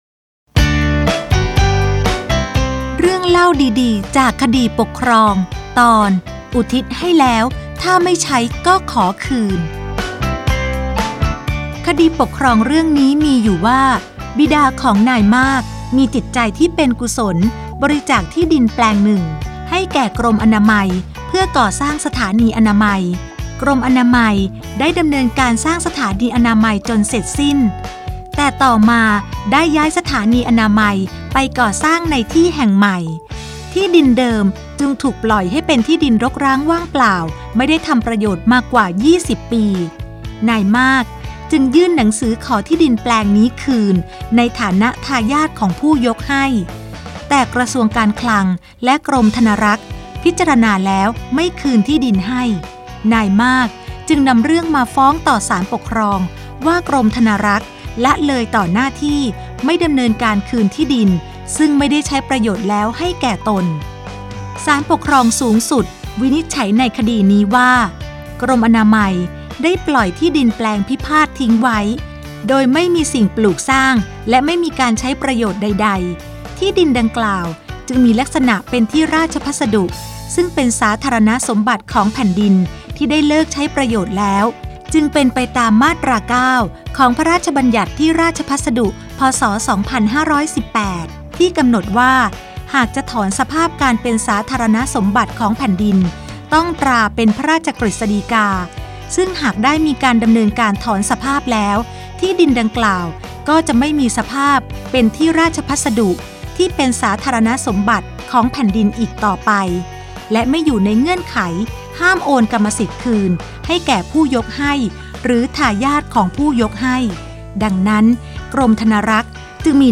สารคดีวิทยุ ชุด เรื่องเล่าดีดีจากคดีปกครอง - อุทิศให้แล้ว...ถ้าไม่ใช้ก็ขอคืน